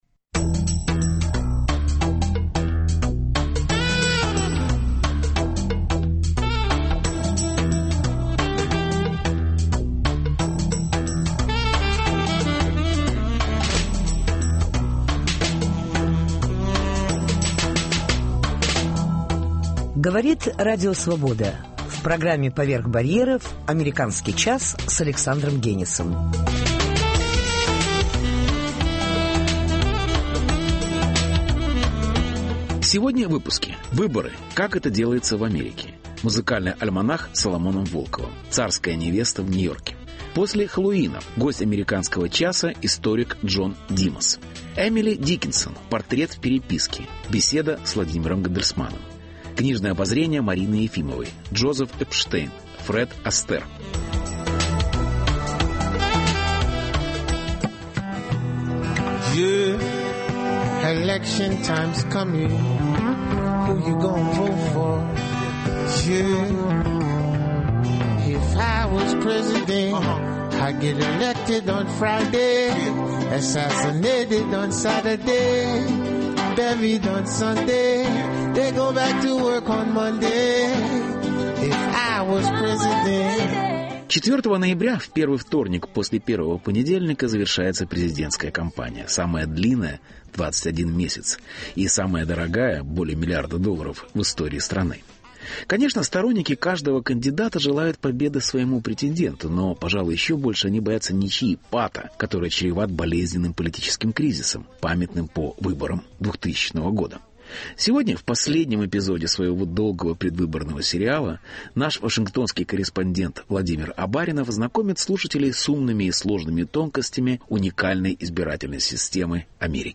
Репортаж. Выборы: как это делается в Америке.
Интервью.